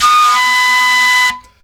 FLUTELIN13.wav